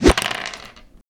roll.wav